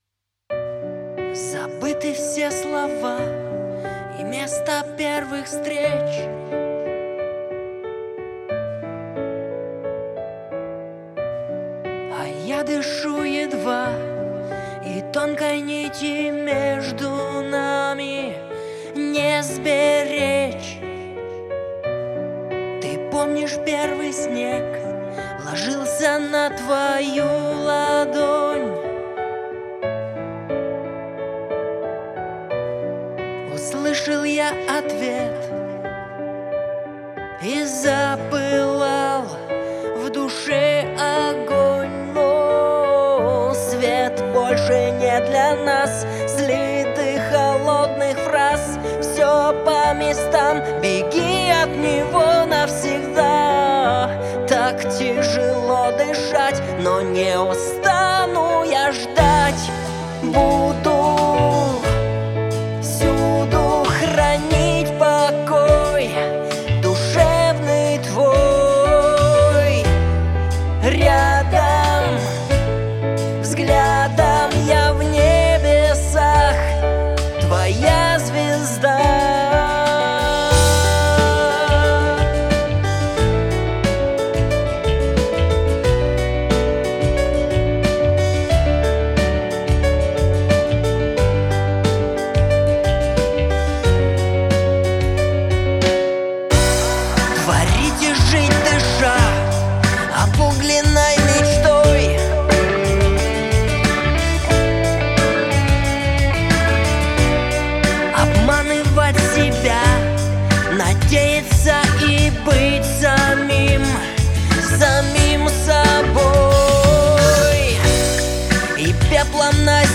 под гитару записали голос. я неспешно фанерку наковырял за несколько дней, переписали голос, свел, отдал - все довольны.